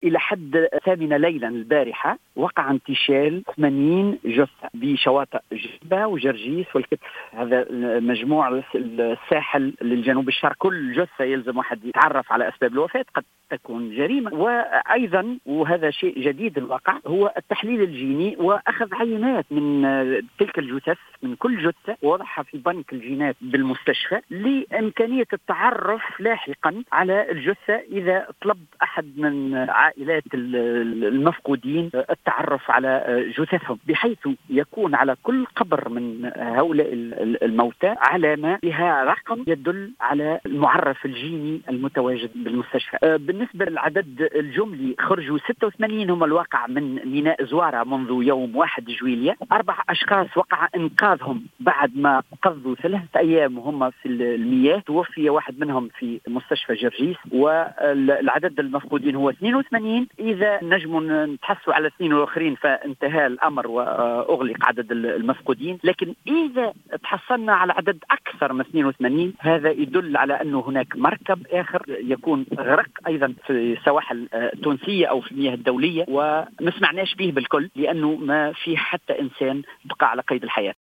تصريح للجوهرة أف أم